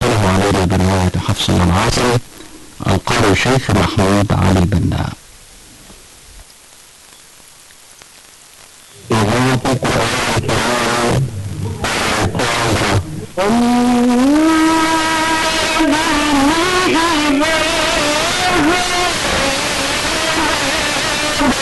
I do not know, but today I have a similar receiving on 87,6 MHz from Egyt.